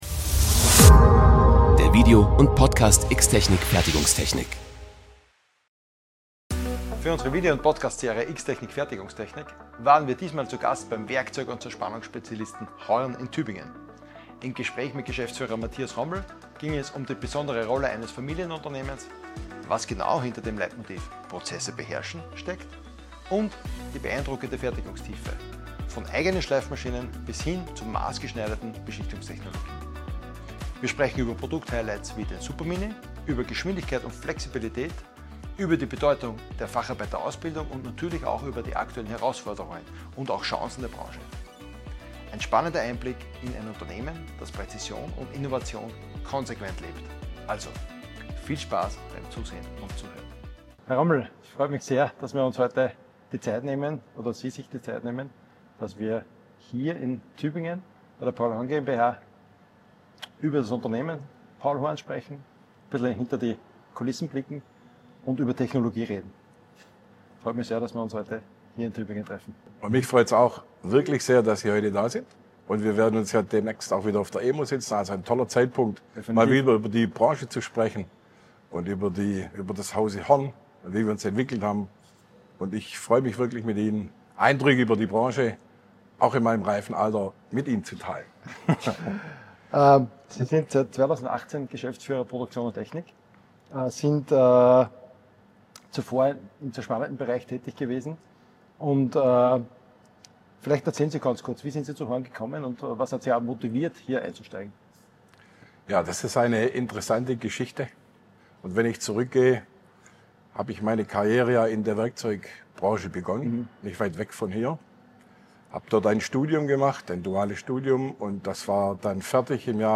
Im Interview spricht er über die besondere Kultur eines Familienunternehmens, die Philosophie „Prozesse beherrschen“, die Bedeutung von High-End-Produktion mit Top-Fachkräften, technologische Innovationen wie den Supermini und die aktuellen Herausforderungen der Branche.